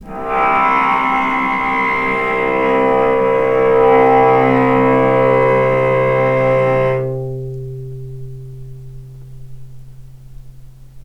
vc_sp-C2-mf.AIF